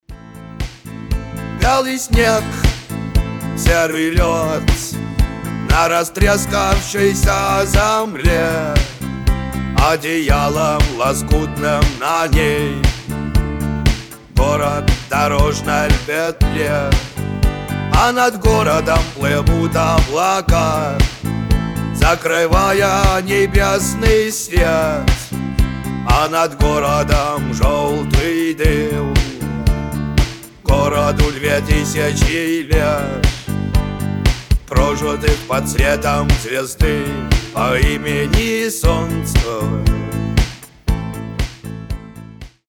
ИИ ковёр